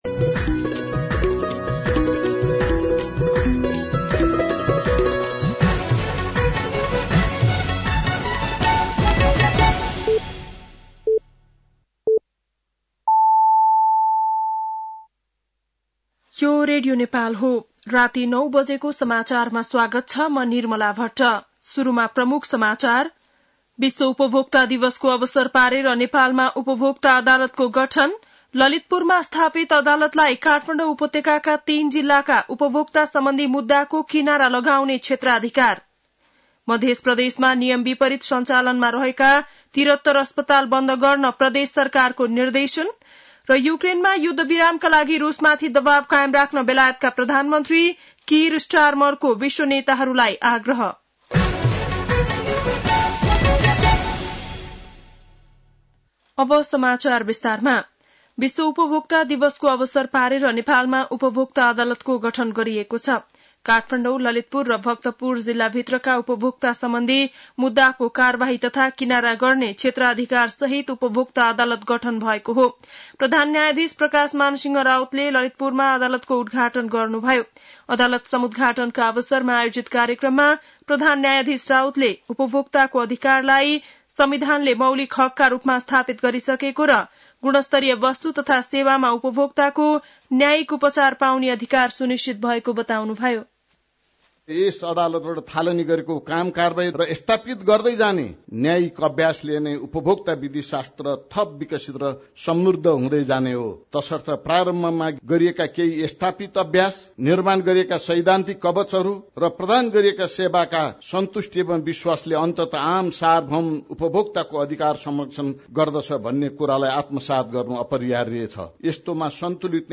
बेलुकी ९ बजेको नेपाली समाचार : २ चैत , २०८१